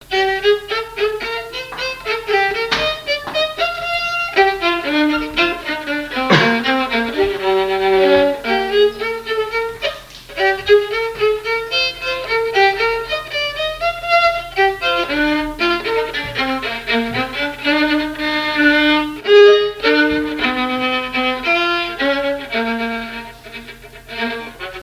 Mémoires et Patrimoines vivants - RaddO est une base de données d'archives iconographiques et sonores.
danse : quadrille : galop
Le quadrille et danses de salons au violon
Pièce musicale inédite